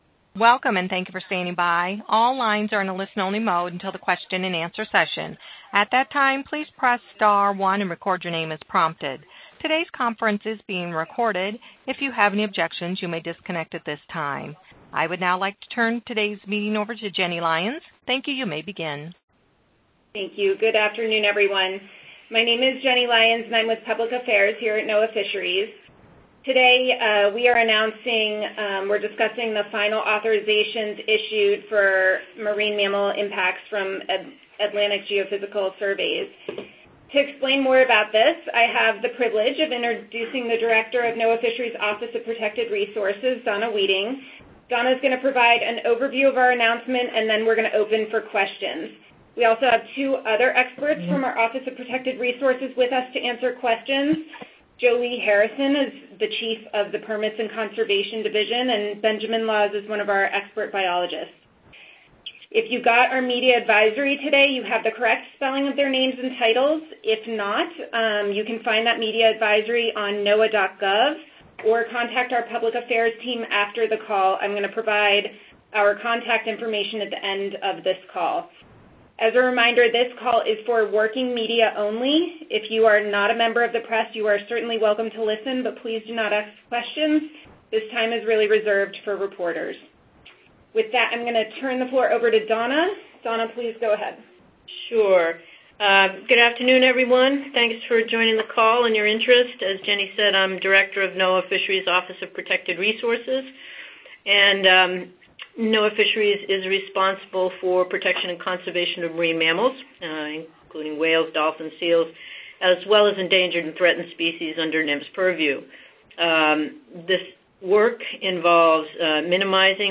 NOAA Fisheries will hold a media call on the final authorizations issued today under the Marine Mammal Protection Act for the incidental harassment of marine mammals, and associated mitigation measures, by companies proposing to conduct geophysical surveys in support of hydrocarbon exploration in the Atlantic Ocean.